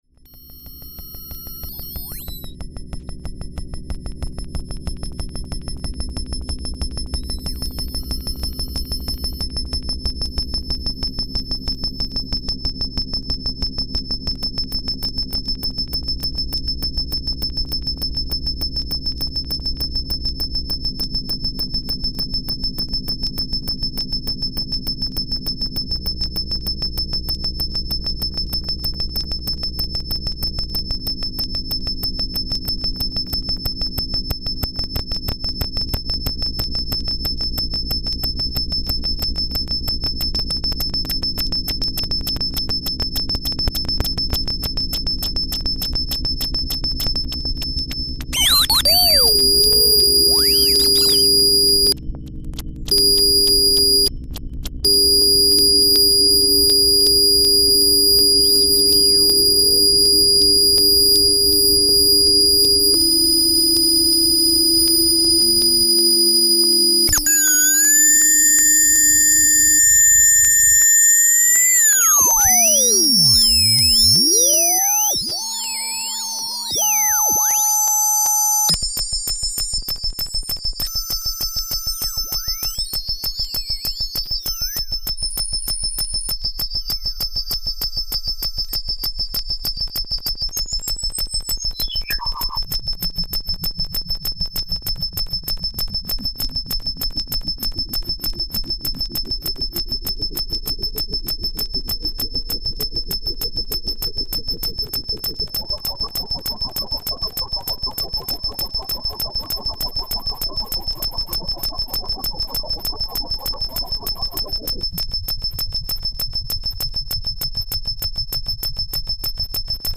prepared mixer
laptop
August 12, 2004 @ Studio 2 Akademie Schloss Solitude.
No effect and EQ is apply on the recorded track.